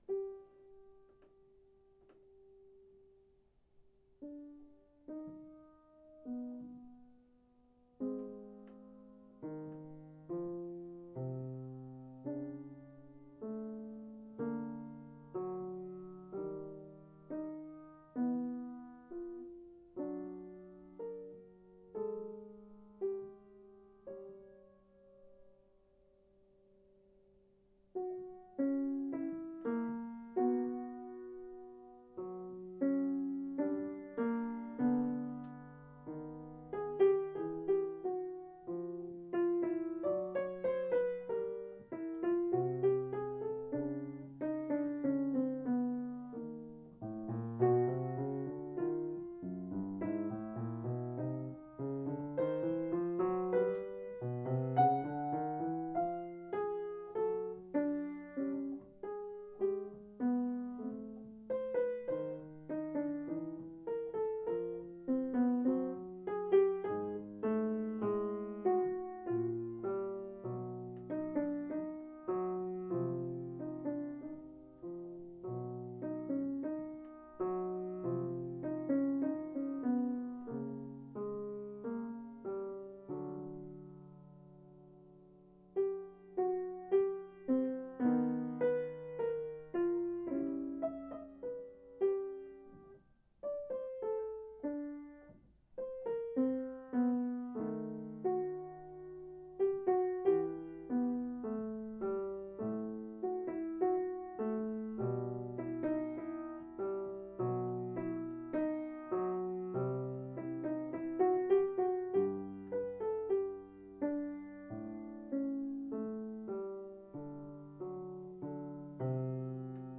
improv 1-2-13